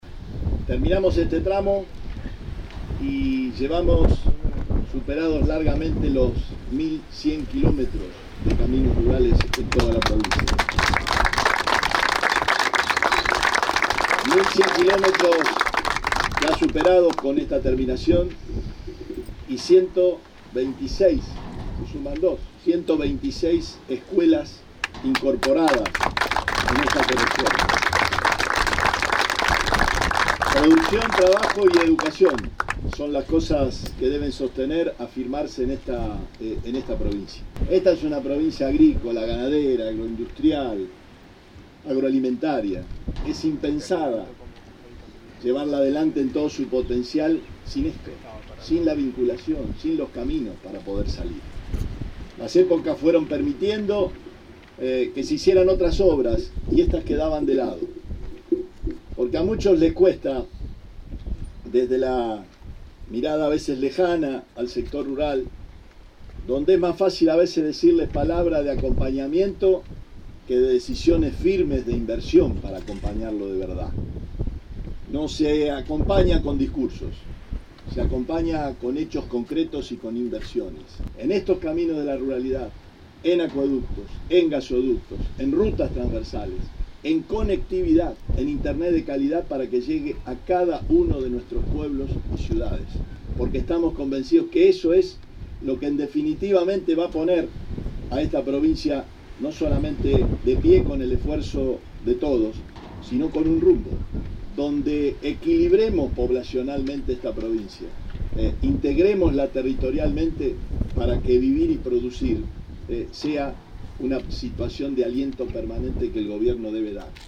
Declaraciones Camino de la Ruralidad